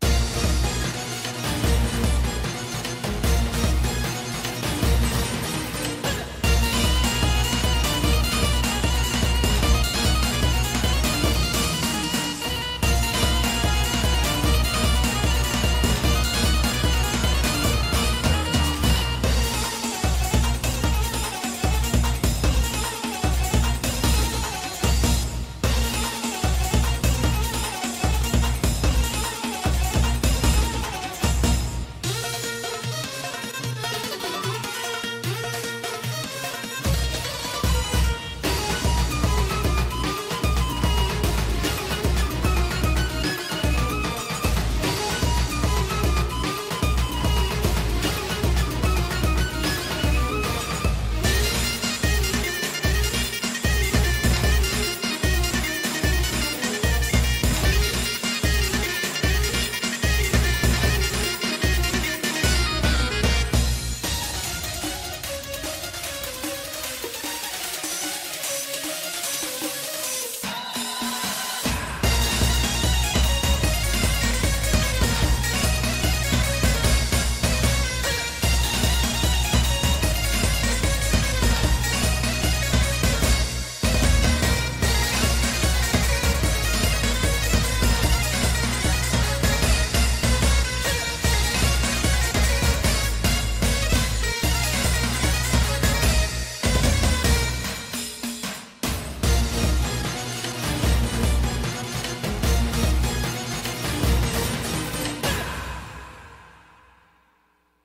BPM150
MP3 QualityMusic Cut